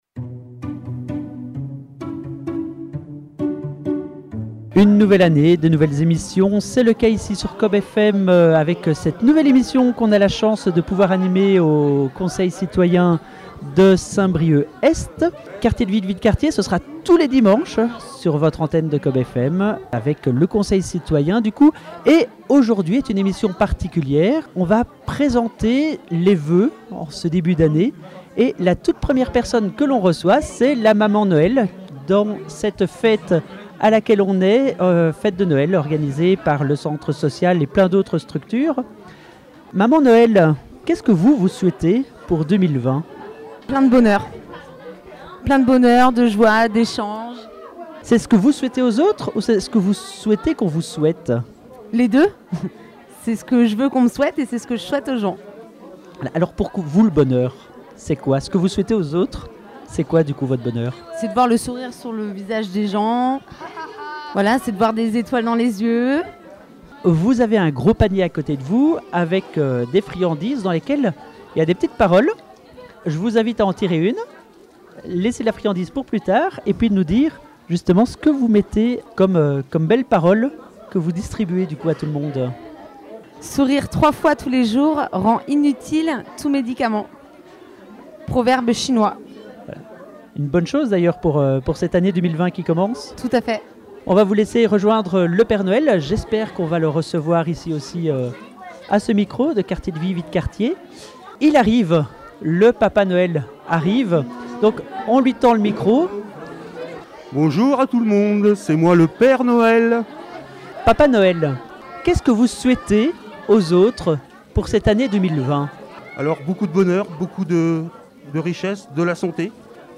habitants et bénévoles à l’occasion des fêtes de Noël au Centre Social du Plateau « Cap Couleurs »